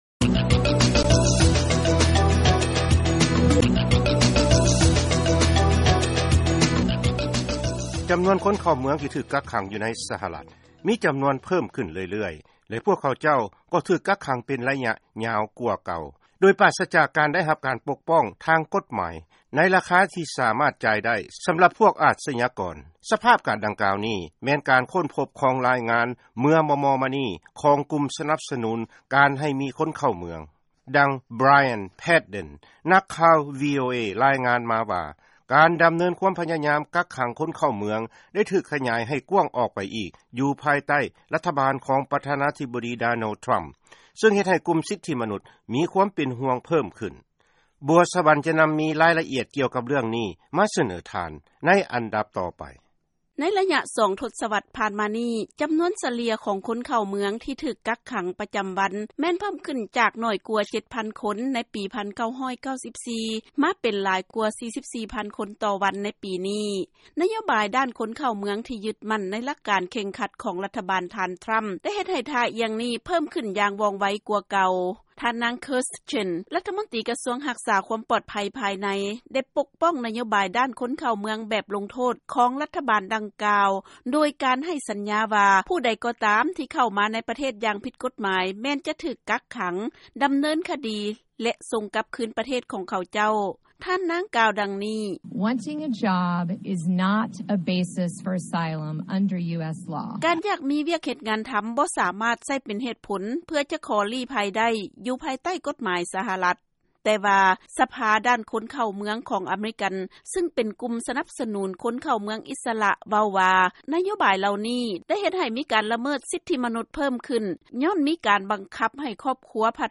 ເຊີນຟັງລາຍງານເລື້ອງການຈັບຄົນເຂົ້າເມືອງຜິດກົດໝາຍ ໃນ ສຫລ